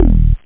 303-drop1.mp3